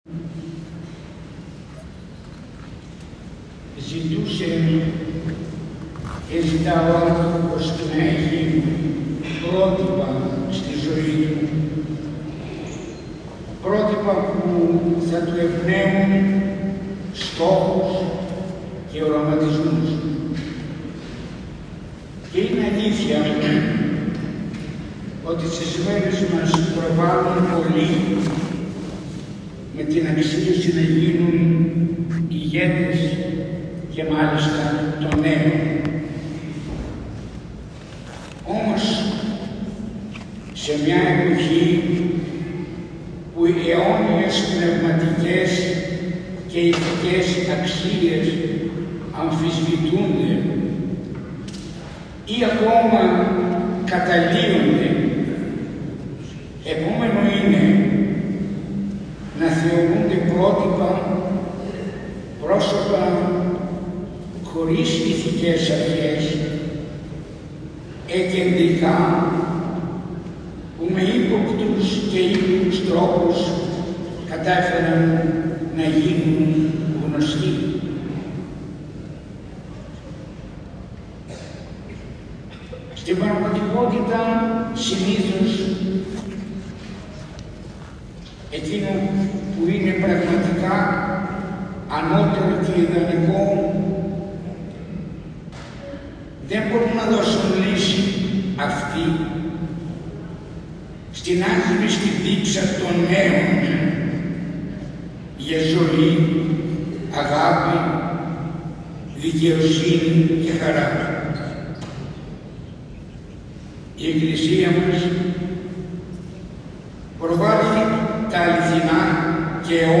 Επίκεντρο των λατρευτικών εκδηλώσεων, ο περικαλλής Ιερός ναός των Τριών Αγίων Ιεραρχών στην πυκνοκατοικημένη Ανατολική Θεσσαλονίκη, περιοχή «Βούλγαρη», τελέστηκε η πανηγυρική Αρχιερατική Θεία Λειτουργία ιερουργούντος του Μητροπολίτου Βρυούλων κ. Παντελεήμονος .
Ακούστε το κήρυγμα του Μητροπολίτου Βρυούλων.